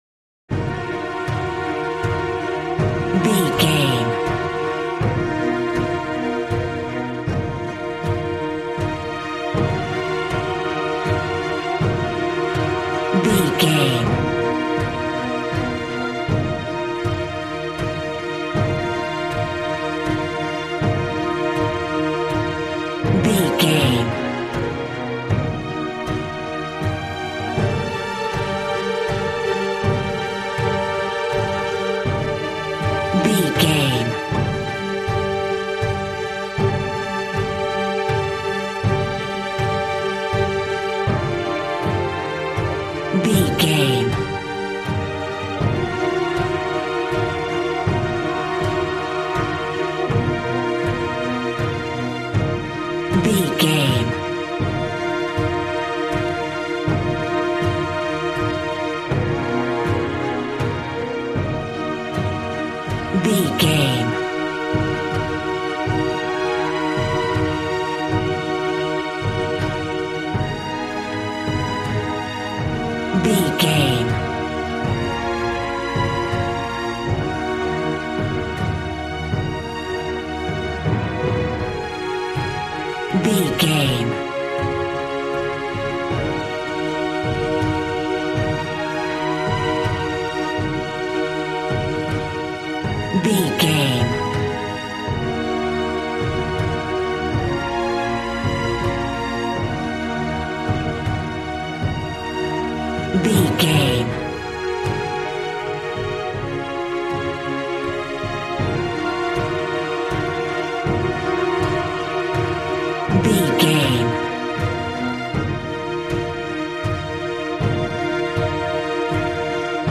Aeolian/Minor
E♭
suspense
piano
synthesiser